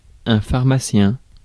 Ääntäminen
IPA: [faʁ.ma.sjɛ̃]